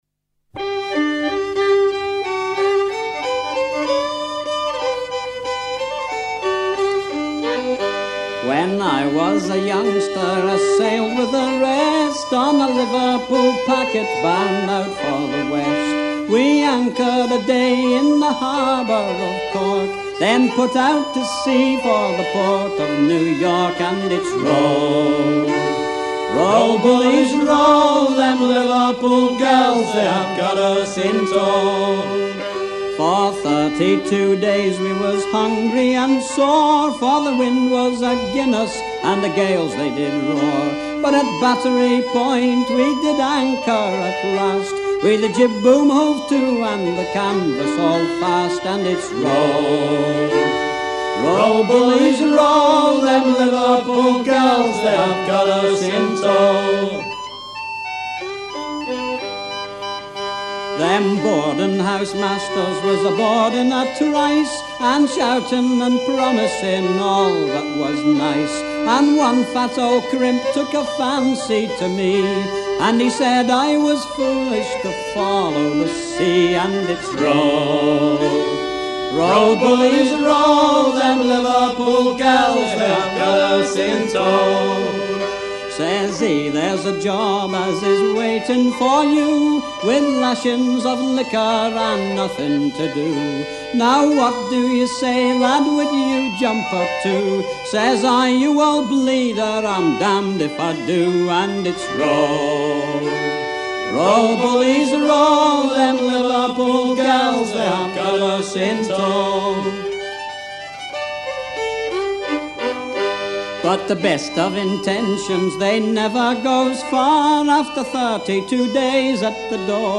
Fonction d'après l'analyste gestuel : à virer au cabestan ;
Genre strophique